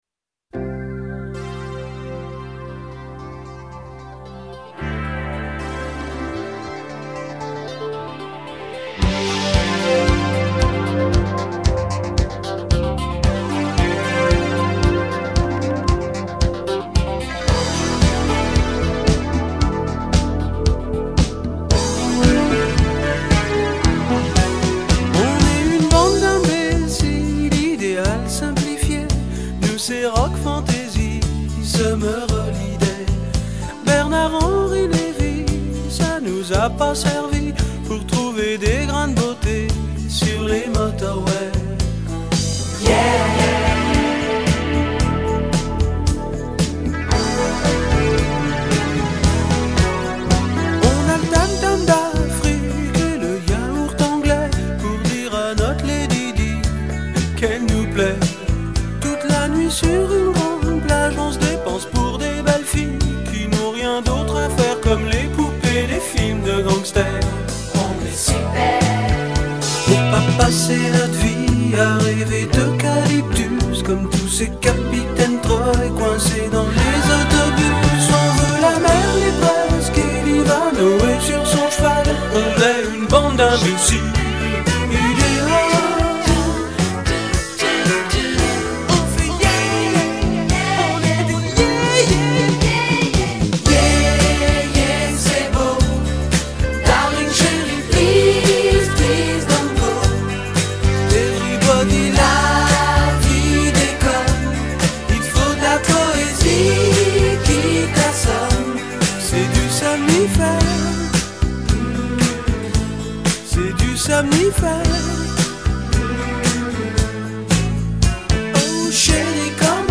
tonalité SOL majeur